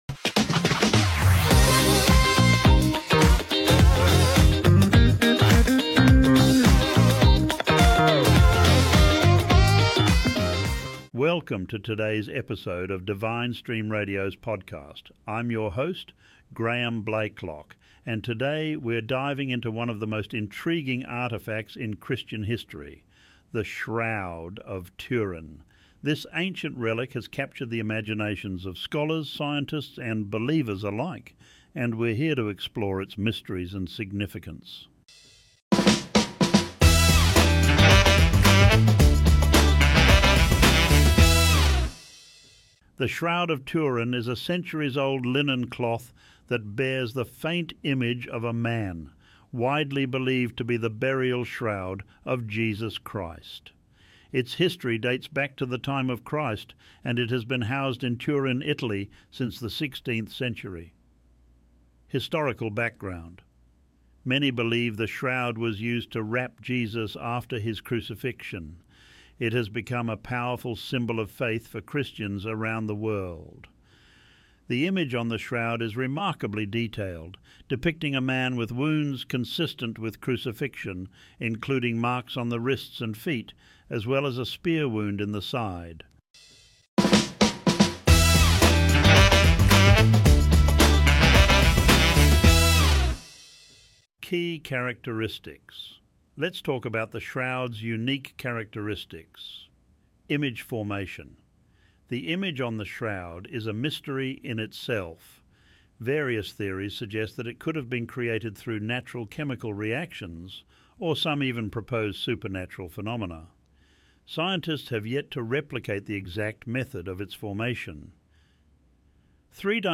Join us as we delve into the enigmatic Shroud of Turin, exploring its history, scientific studies, and the profound questions it raises about faith and authenticity. Each episode features expert interviews and captivating discussions that uncover the mysteries surrounding this iconic relic.